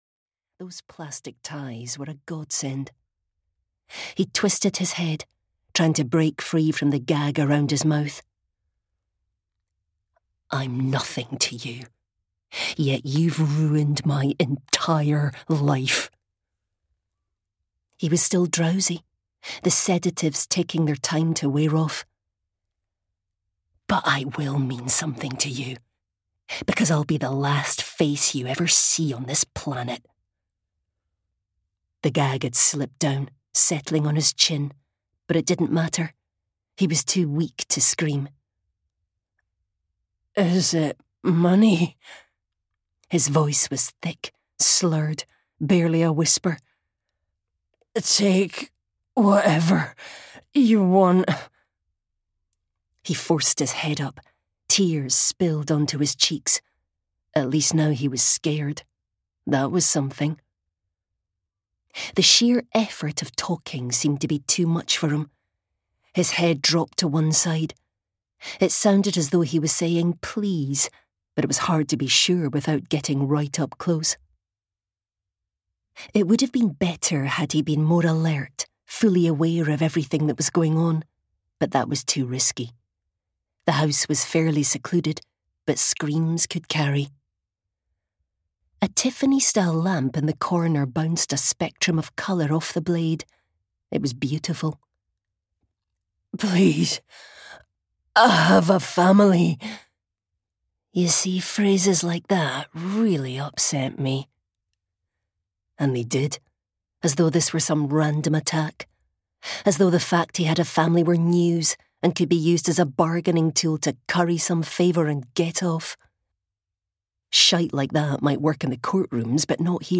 The Quiet Ones (EN) audiokniha
Ukázka z knihy